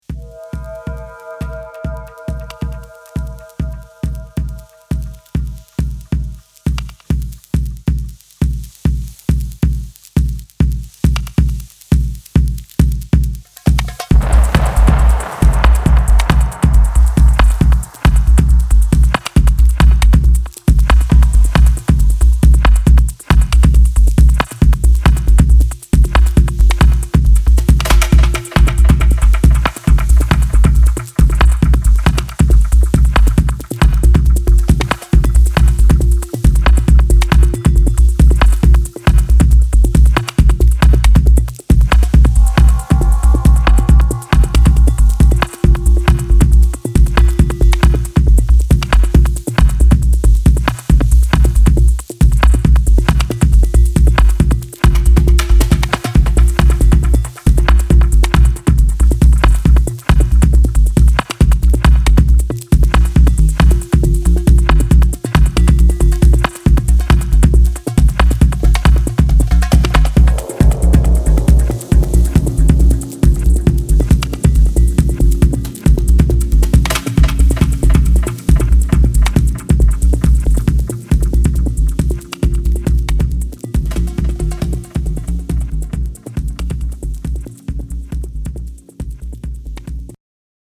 ベースラインが次第に三連を刻み、アシッドにブーストされてゆくトランシー&パーカッシヴな強力チューン